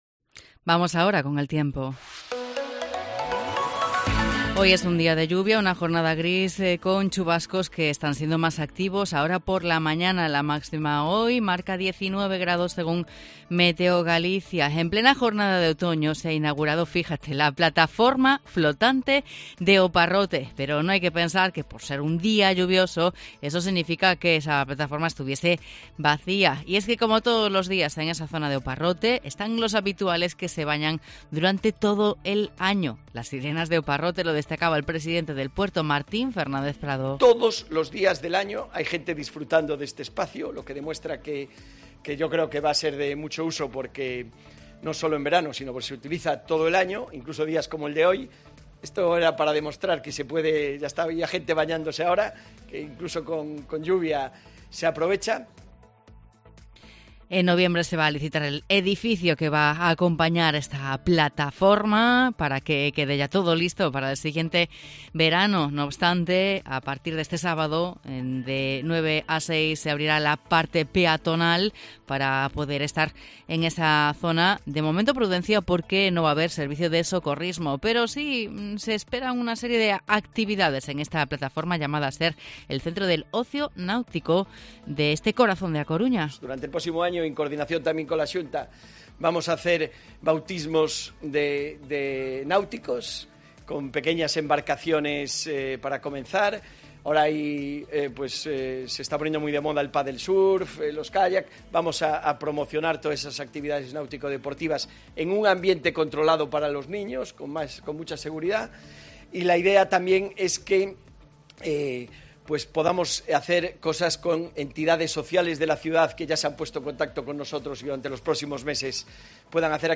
Inauguración de la Plataforma flotante de O Parrote (A Coruña)